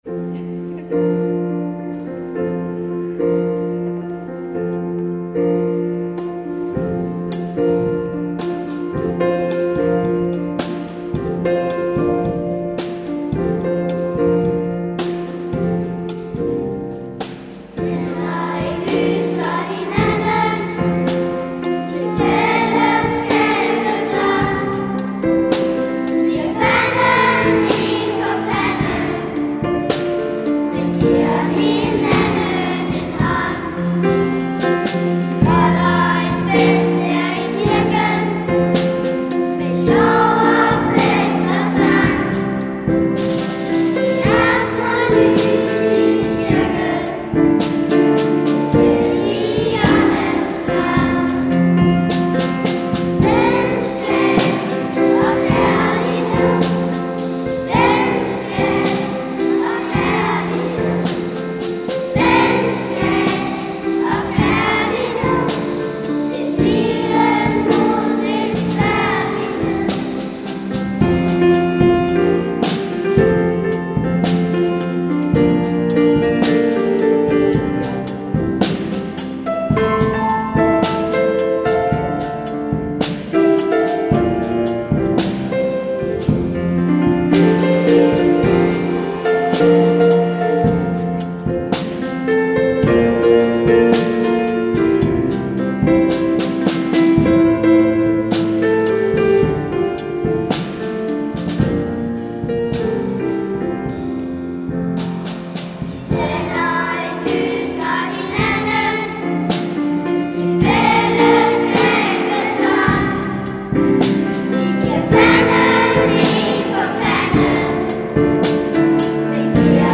Børnetjenesten i Messiaskirken i Charlottenlund synger deres egen pinsesang.
Piano
Trommer